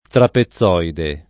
trapezoide [ trape ZZ0 ide ]